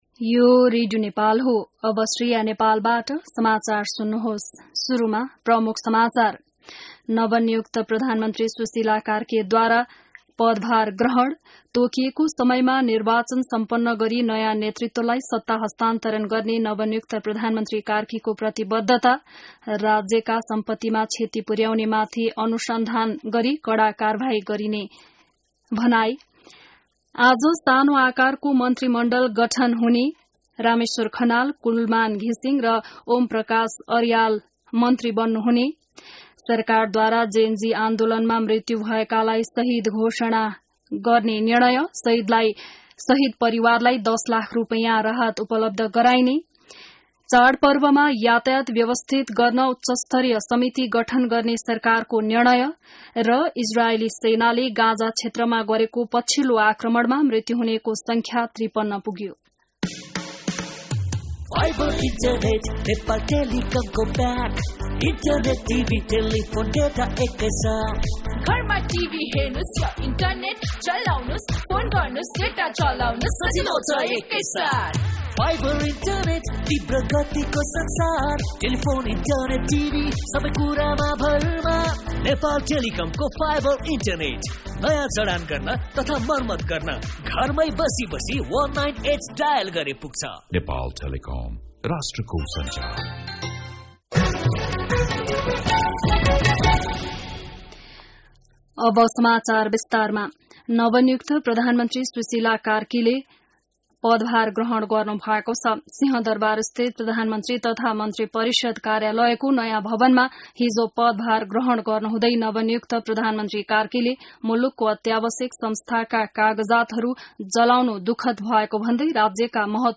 बिहान ७ बजेको नेपाली समाचार : ३० भदौ , २०८२